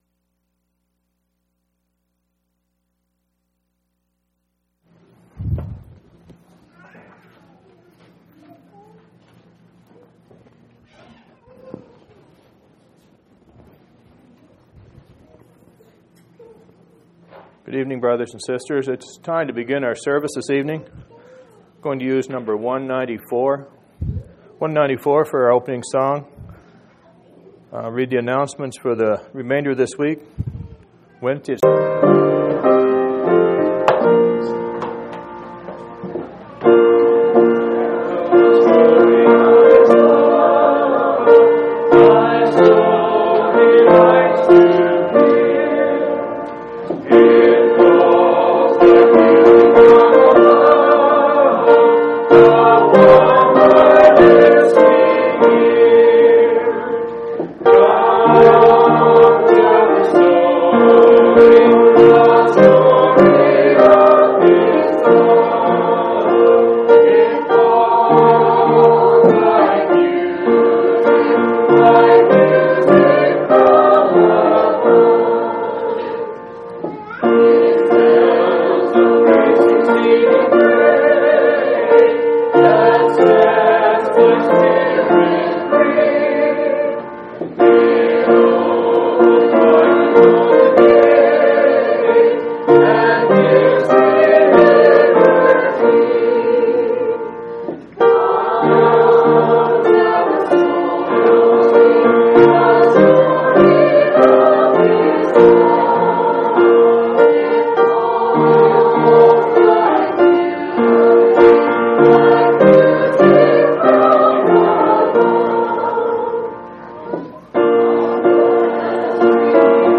8/24/2003 Location: Phoenix Local Event